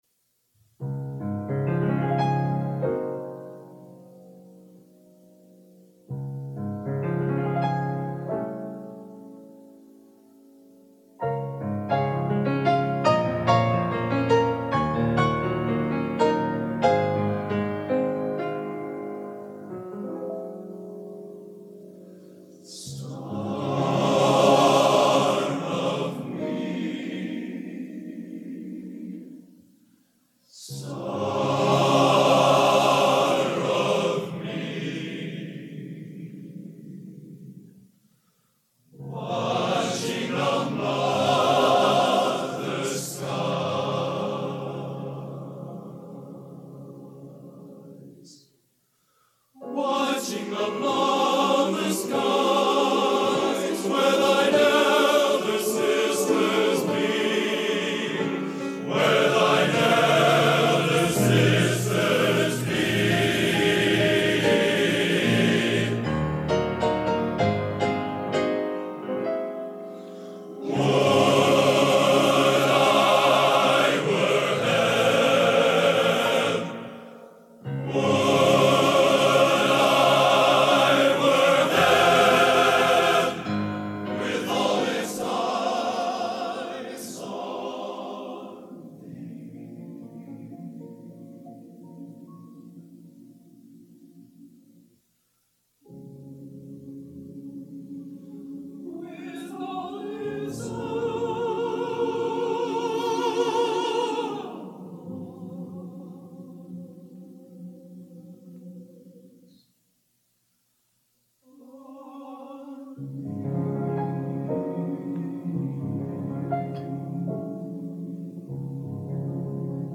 Genre: Classical Schmalz | Type: Studio Recording